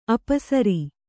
♪ apasari